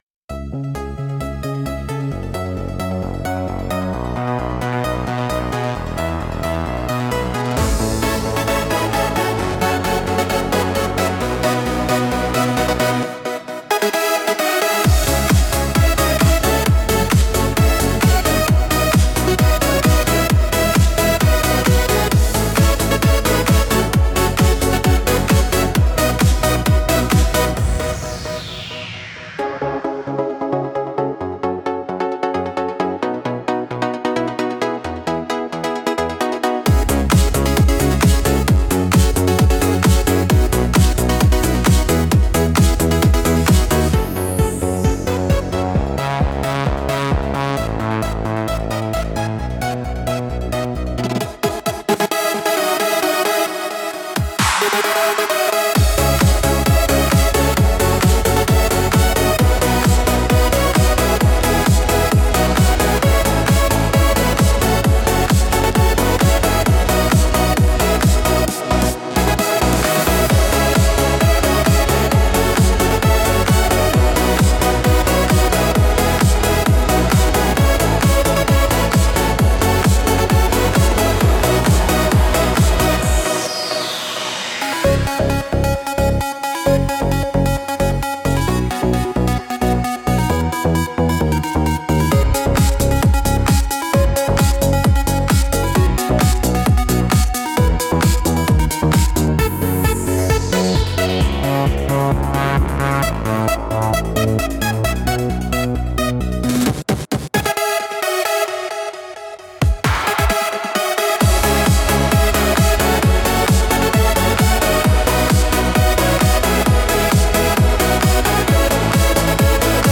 Instrumental - Pixel Paradise 2.56